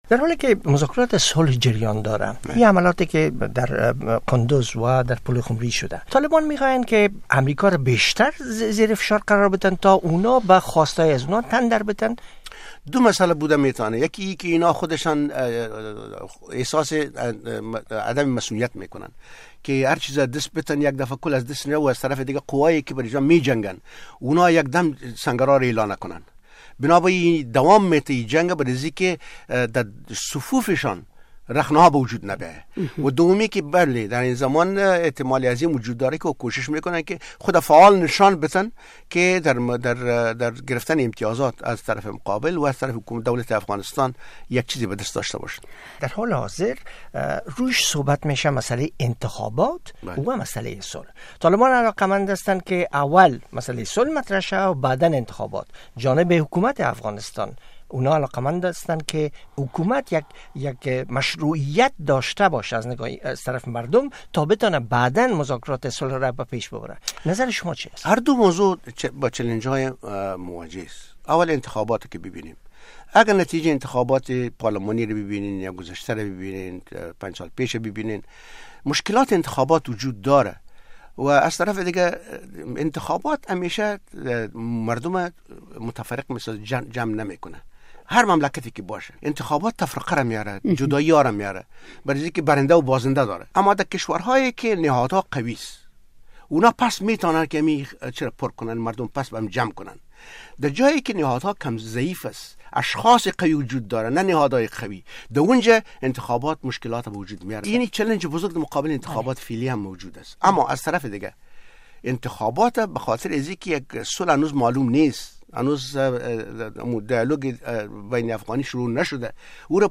The URL has been copied to your clipboard No media source currently available 0:00 0:04:26 0:00 لینک دانلود 128 kbps | ام‌پی ۳ 64 kbps | ام‌پی ۳ برای شنیدن مصاحبه در صفحۀ جداگانه اینجا کلیک کنید